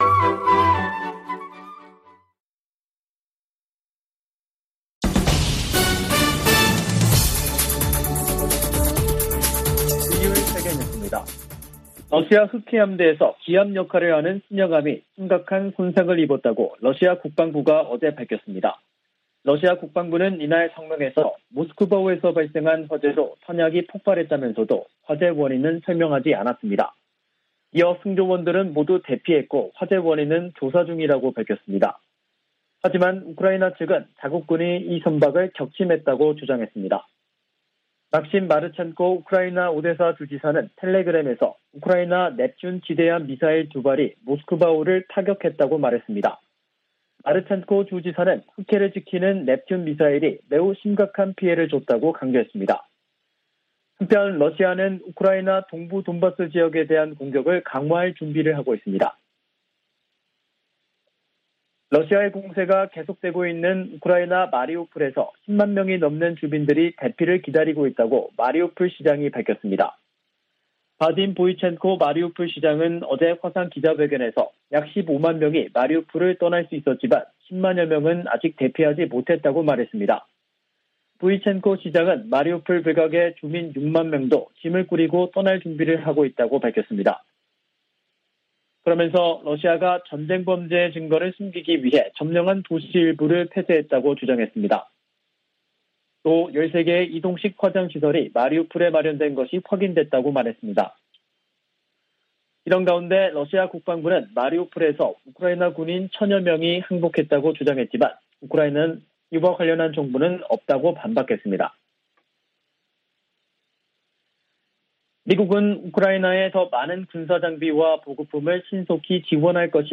VOA 한국어 간판 뉴스 프로그램 '뉴스 투데이', 2022년 4월 14일 3부 방송입니다. 미 7함대는 에이브러햄 링컨 항모가 동해에서 일본 자위대와 연합훈련을 실시하고 있다며 북한에 적대적인 의도는 없다고 밝혔습니다. 미 상·하원이 중국 견제 법안에 관한 조율 절차에 들어갔습니다.